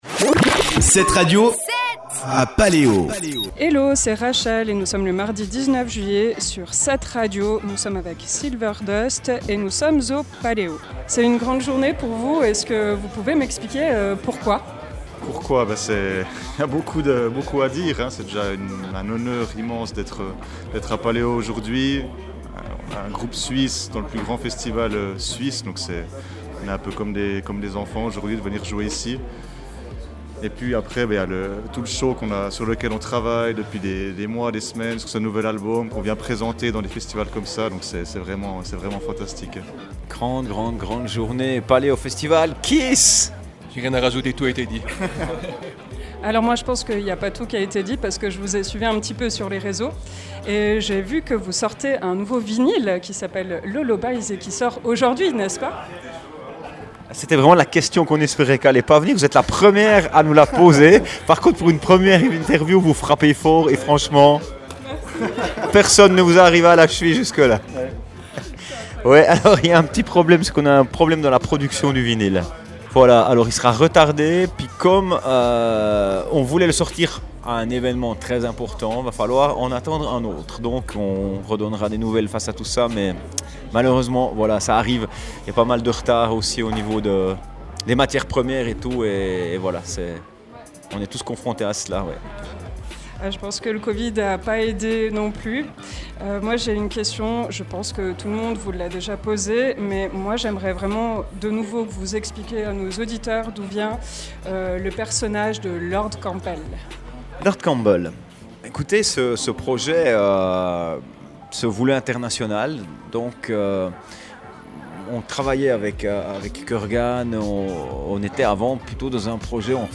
Paléo 2022 – Interview Silver Dust
Paleo22-Silverdust-Itw.mp3